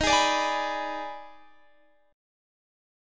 EbM7sus4#5 Chord
Listen to EbM7sus4#5 strummed